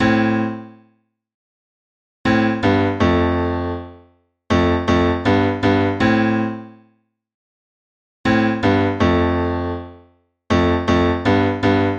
只是低重音的钢琴音符
描述：这首曲子只是我上传的另一首钢琴曲的重音低音。使用Logic录制的
Tag: 80 bpm Pop Loops Piano Loops 2.02 MB wav Key : Unknown